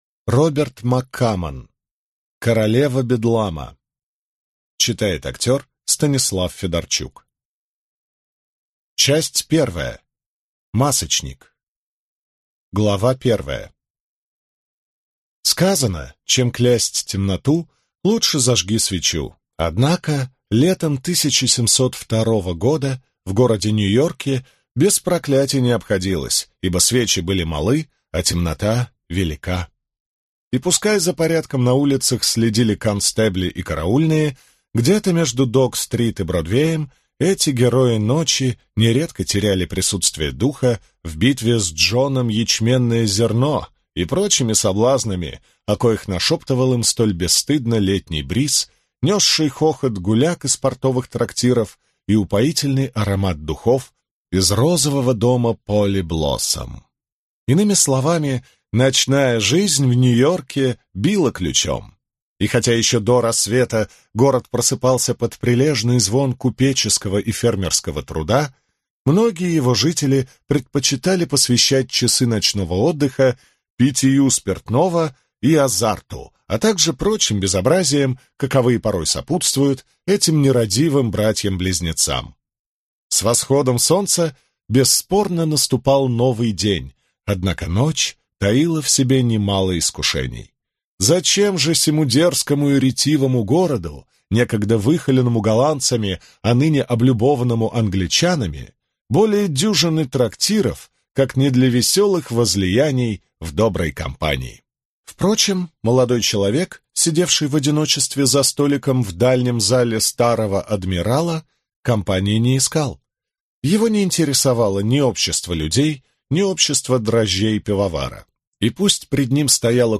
Аудиокнига Королева Бедлама | Библиотека аудиокниг
Прослушать и бесплатно скачать фрагмент аудиокниги